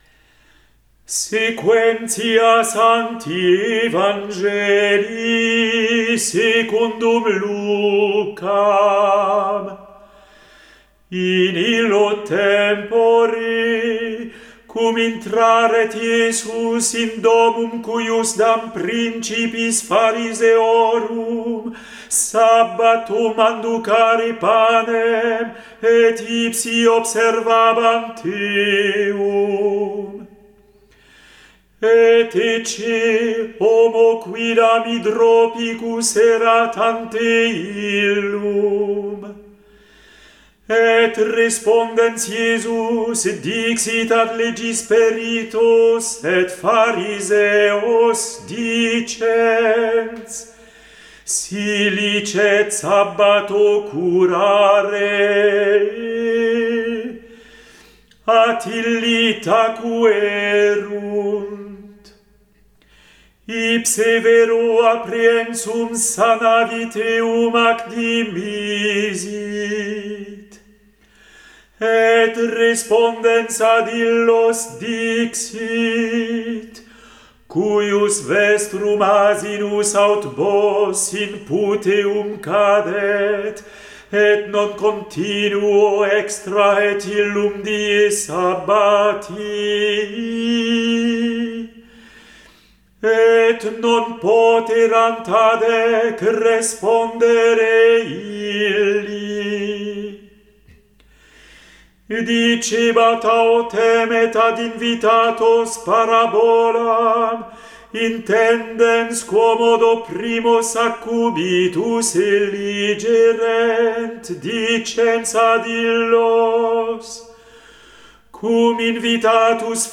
Evangelium